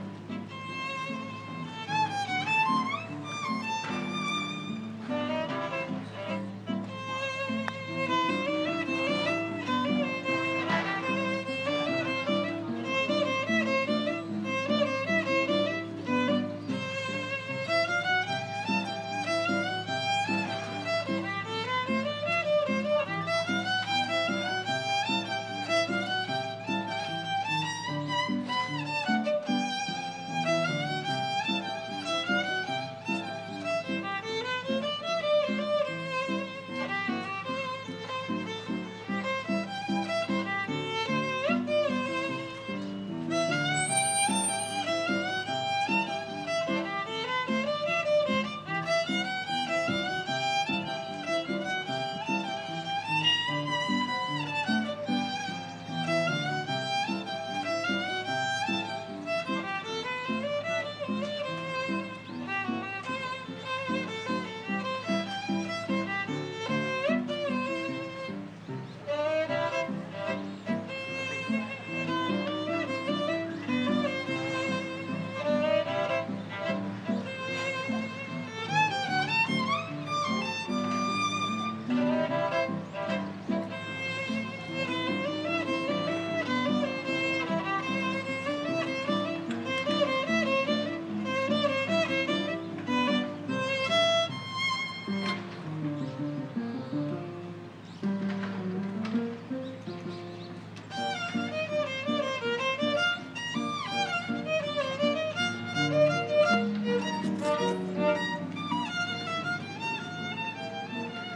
Violinist